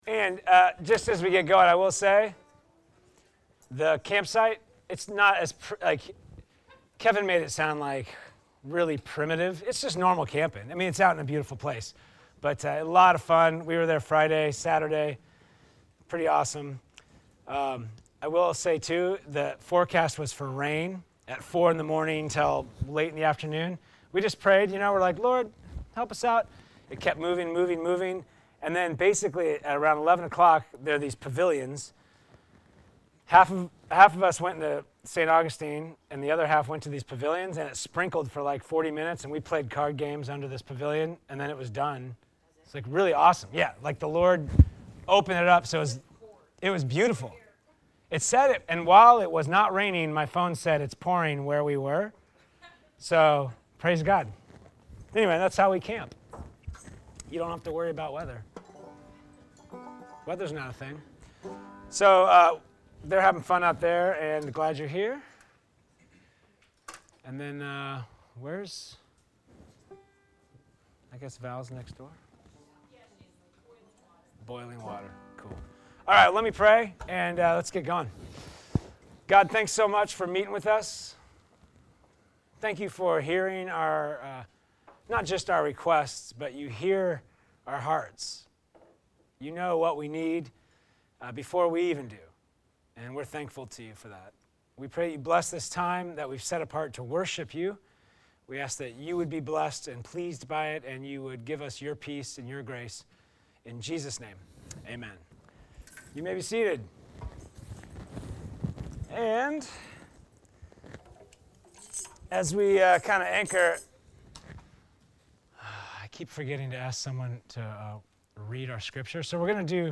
NCCO Sermons